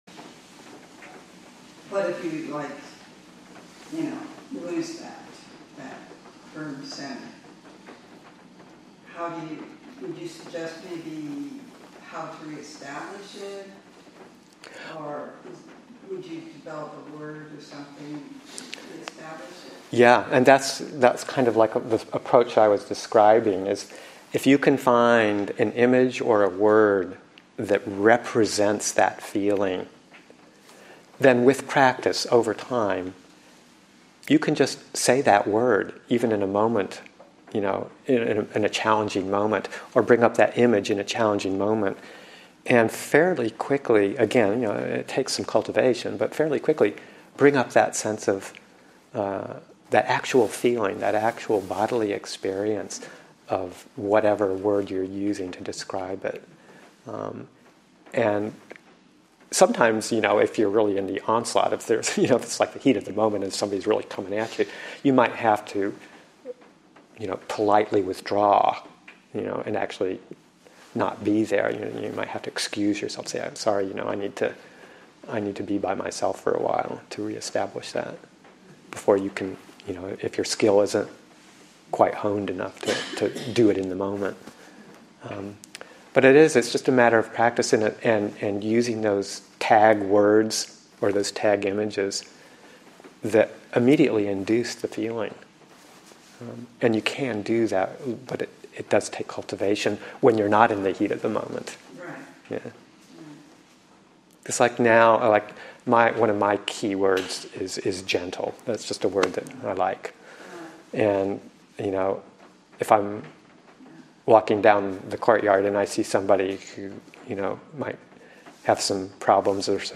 Developing Skill in Reflective Meditation, Session 1 – Dec. 1, 2019